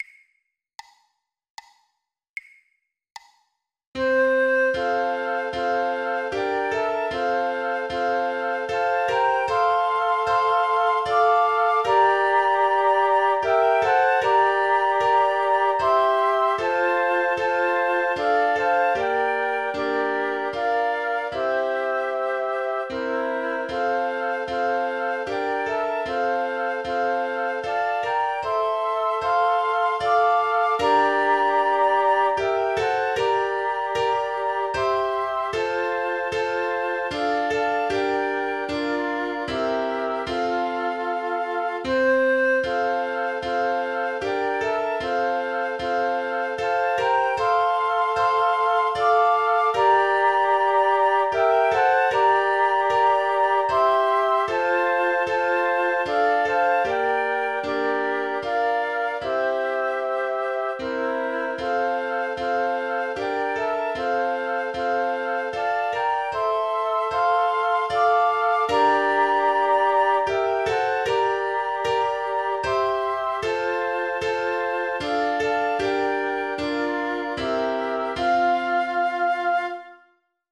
Playback Crowd 2 .mp3 76 bpm,
away_in_a_manger_Flöte-Klavier.mp3